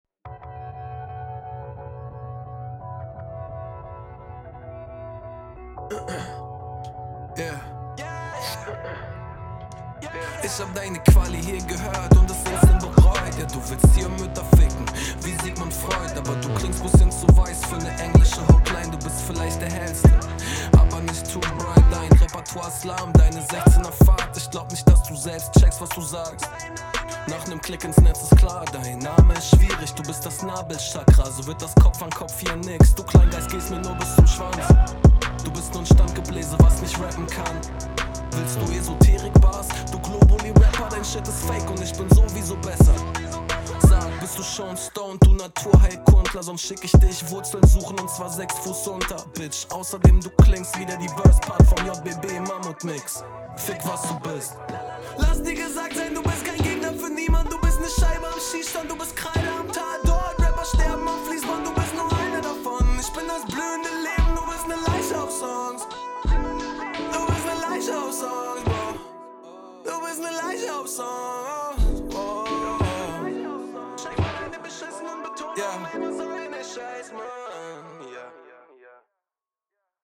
Klingt mega geil, Lines etwas generisch, aber eig ganz funny, cooler auftackt